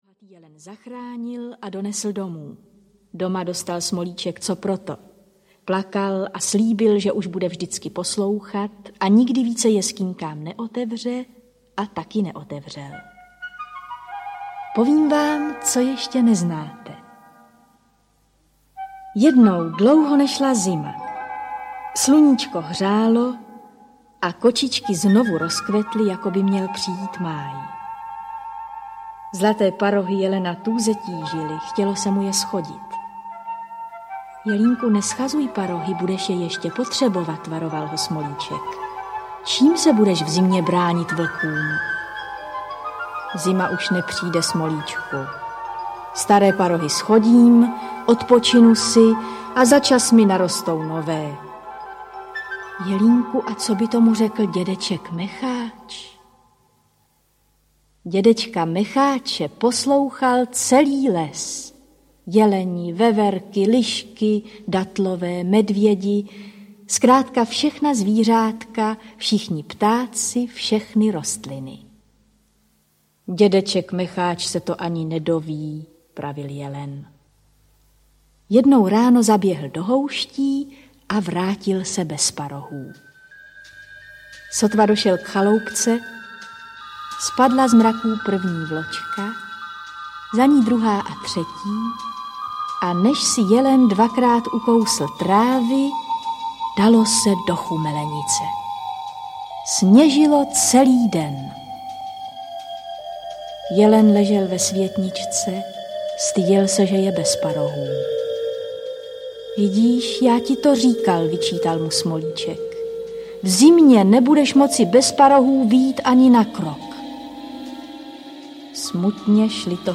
Špalíček pohádek audiokniha
Audiokniha Špalíček pohádek - obsahuje známé pohádky, jejichž autorem je František Hrubín. Účinkují Miloš Kopecký, Dagmar Sedláčková, Zdeněk Štěpánek, Marie Tomášová.
Ukázka z knihy